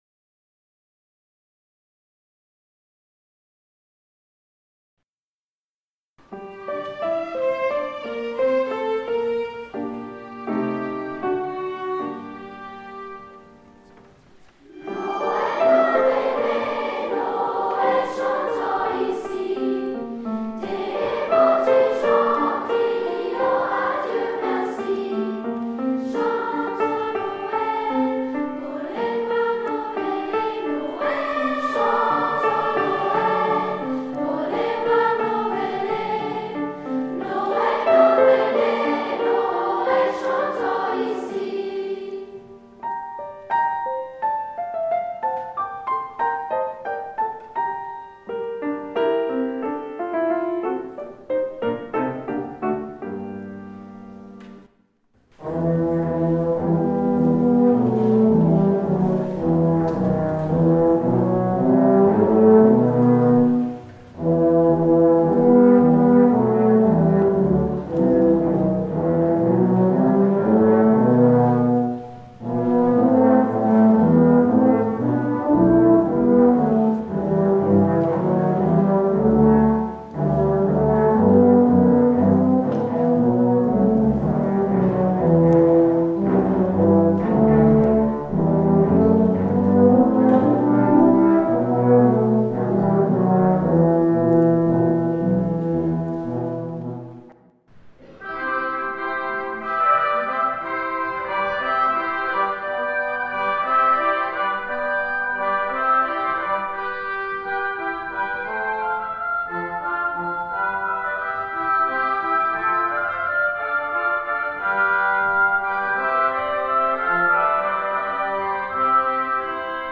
Neben verschiedenen kammermusikalischen Formationen und Solisten traten auch Chor und Orchester vor ihren Familien und Freunden auf.
Es waren Konzerte voller schöner Klänge, voller weihnachtlicher Vorfreude und voller gemeinsamer Momente, die noch lange in Erinnerung bleiben.
Ausschnitte aus dem Adventskonzert der Unterstufe:
Adventskonzert_Unterstufe-2025.mp3